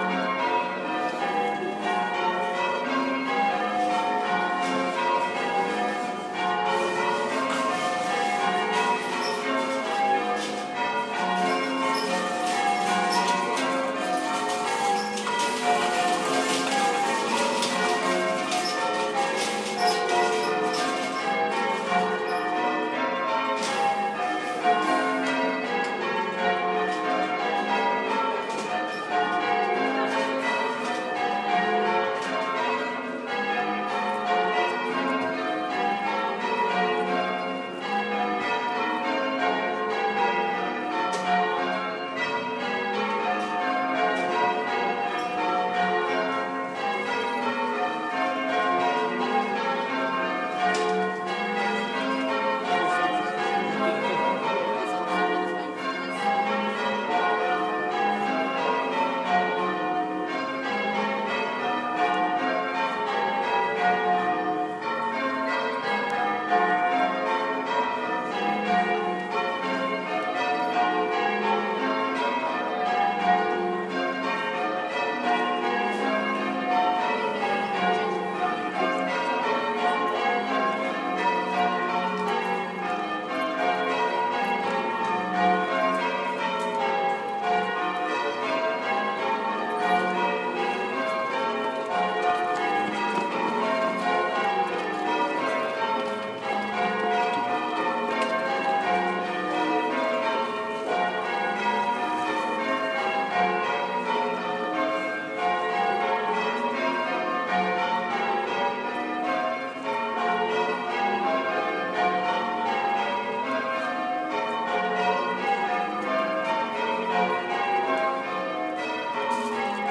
Bells and Drums in Worcester